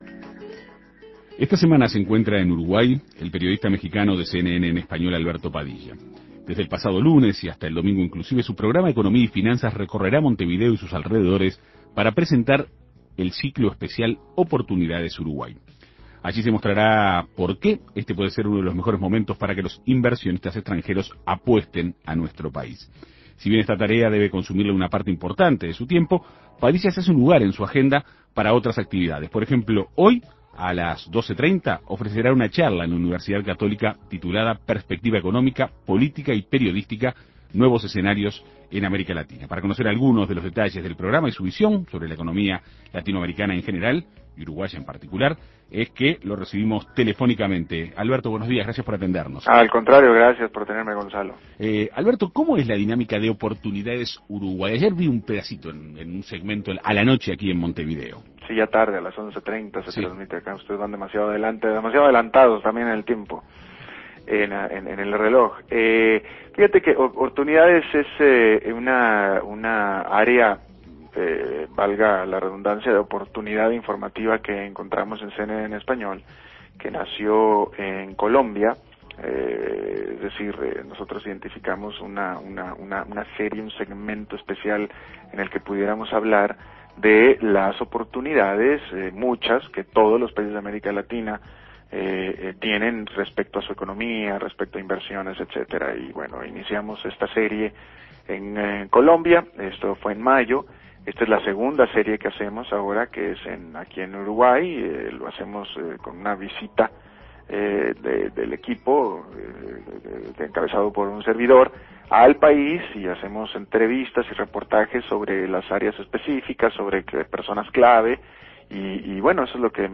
Para conocer algunos de los detalles del programa y su visión sobre la economía latinoamericana en general, y la uruguaya en particular, En Perspectiva Segunda Mañana dialogó con el periodista.